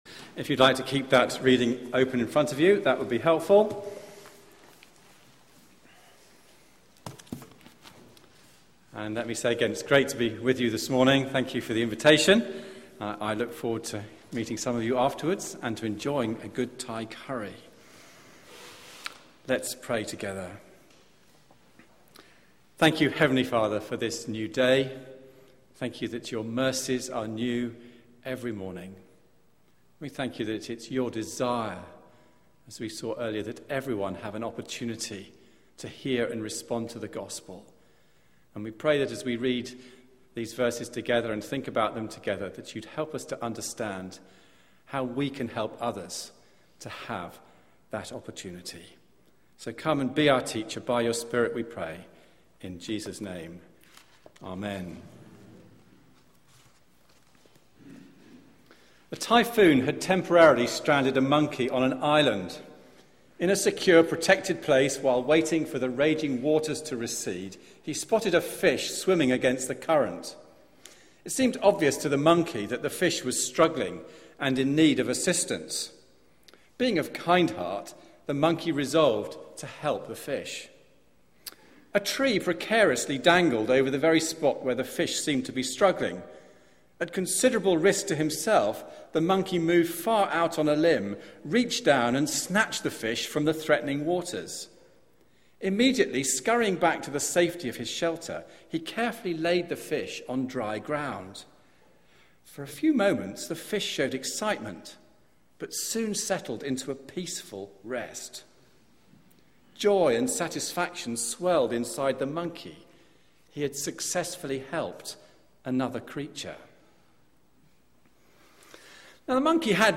Media for 9:15am Service on Sun 03rd Feb 2013 09:15 Speaker
Sermon